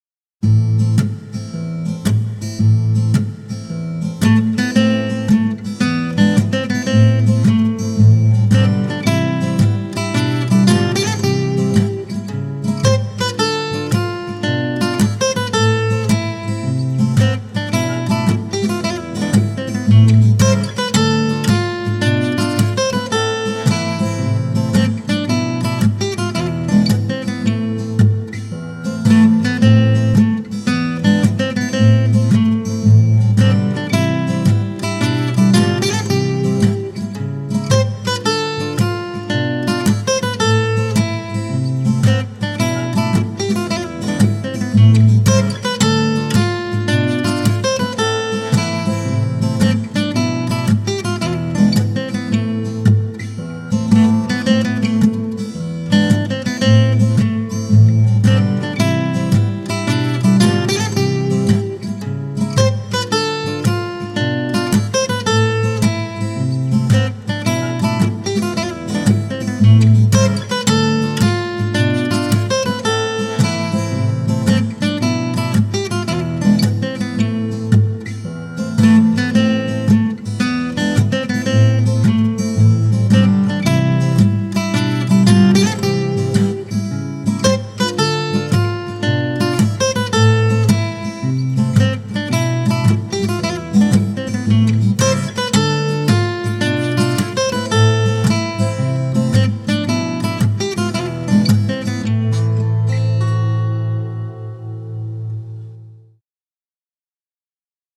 в гитарном исполнении